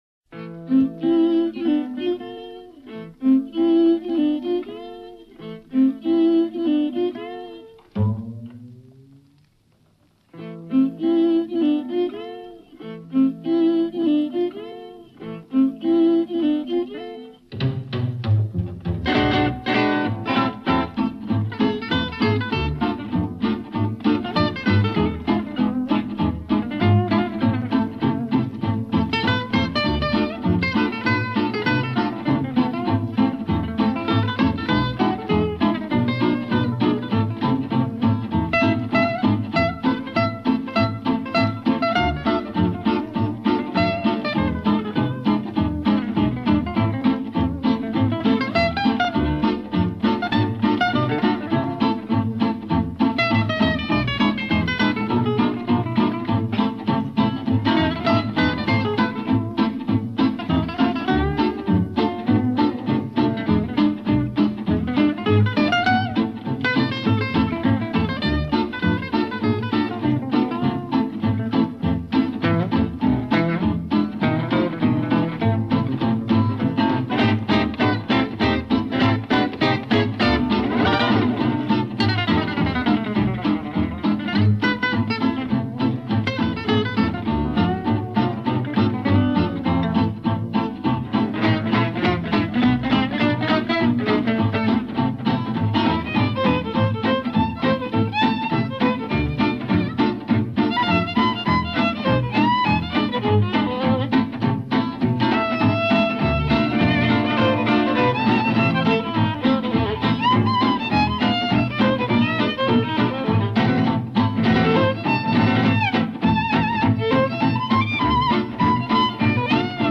Gipsy jazz